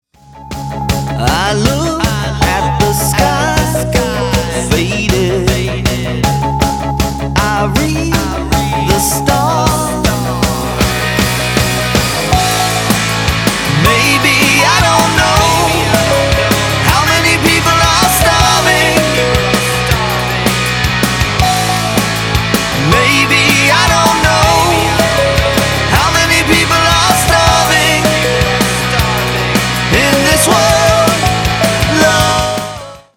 • Качество: 320, Stereo
мужской вокал
громкие
Драйвовые
грустные
красивый мужской голос
Alternative Metal
nu metal
Progressive Metal